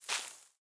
Minecraft Version Minecraft Version 25w18a Latest Release | Latest Snapshot 25w18a / assets / minecraft / sounds / block / big_dripleaf / tilt_up2.ogg Compare With Compare With Latest Release | Latest Snapshot
tilt_up2.ogg